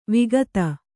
♪ vigata